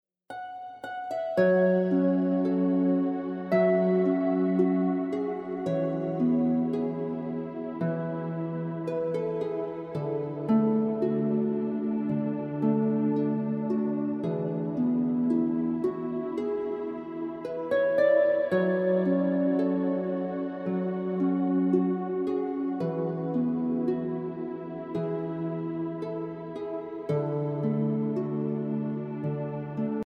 Heavenly Harp Music